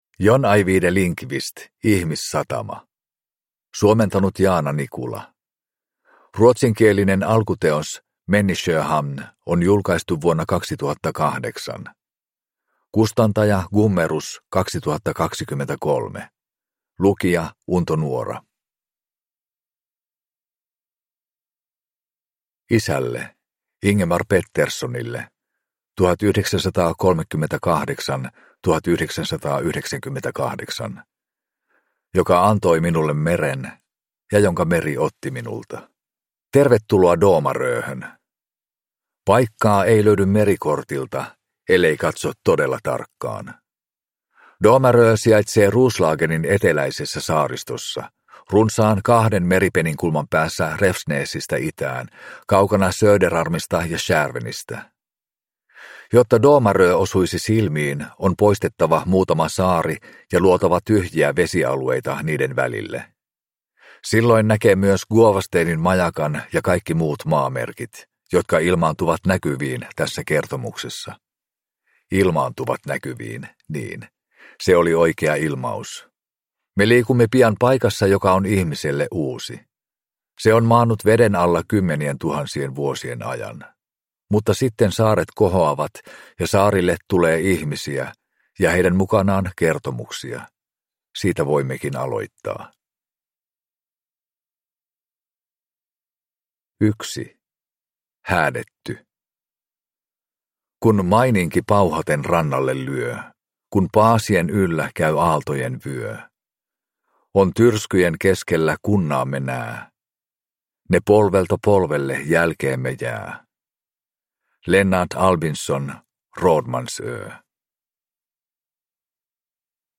Ihmissatama – Ljudbok – Laddas ner